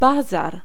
Ääntäminen
Synonyymit attraction excrément bazar Ääntäminen Tuntematon aksentti: IPA: /fwaʁ/ IPA: /fwɑʁ/ Haettu sana löytyi näillä lähdekielillä: ranska Käännös Ääninäyte Substantiivit 1. jarmark Muut/tuntemattomat 2. rynek {m} 3. bazar {m} Suku: f .